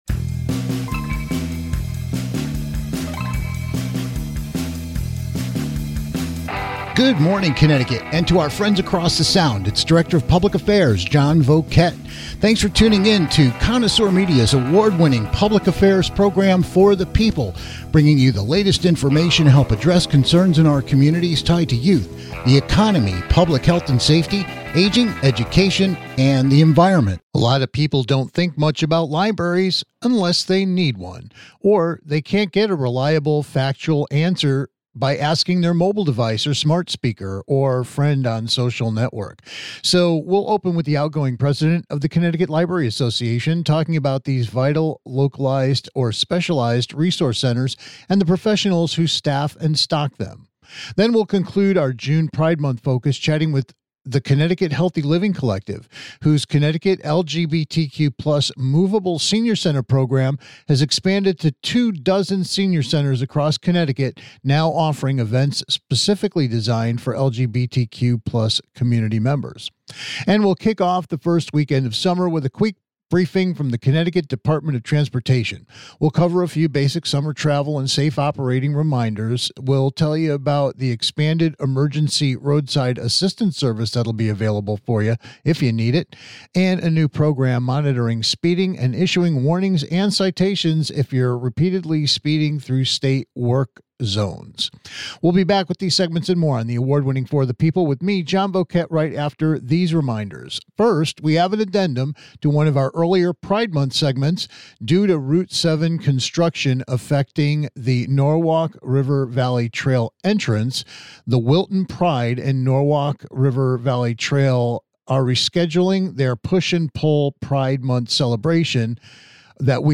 Then we'll conclude our June Pride Month focus chatting with the CT Healthy Living Collective whose CT LGBTQ+ Moveable Senior Center Program has expanded to 24 senior centers across Connecticut now offering events specifically designed for LGBTQ+ community members. And we'll kick off the first weekend of summer with a quick briefing from the CT Dept. of Transportation. We'll cover a few basic summer travel and safe operating reminders, remind you about expanded emergency roadside assistance service, and a new program monitoring speeding and issuing warnings if you're speeding through state road work zones.